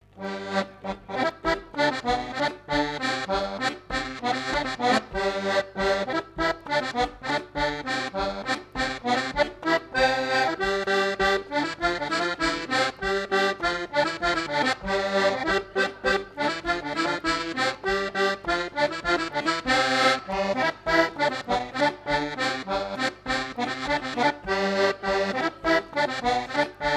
danse : scottich trois pas
Fête de l'accordéon
Pièce musicale inédite